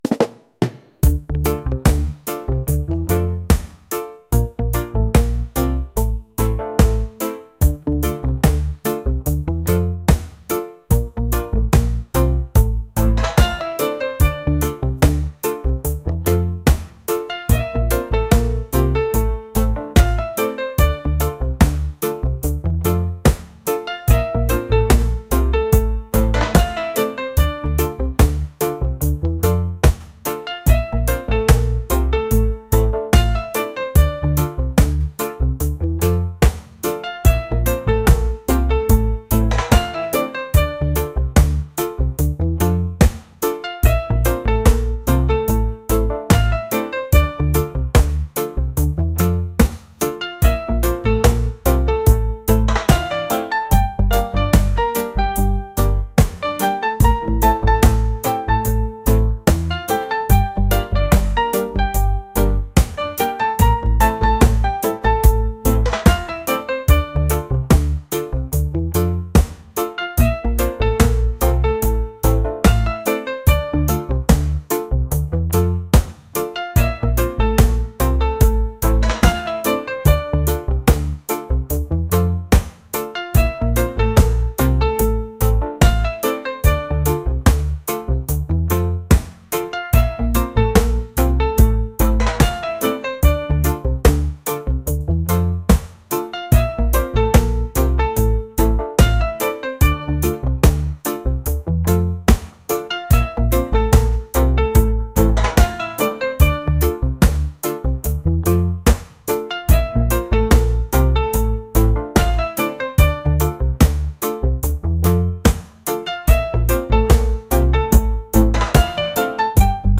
reggae | world | acoustic